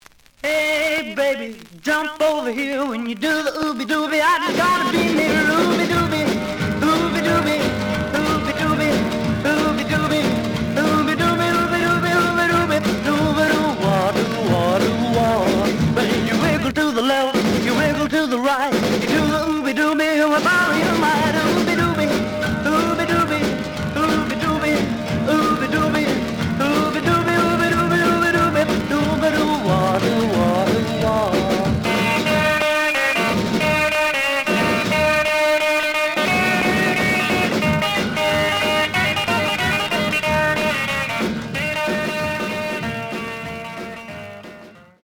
The audio sample is recorded from the actual item.
●Genre: Rhythm And Blues / Rock 'n' Roll
There is a cave on later half of A side, it affect sound.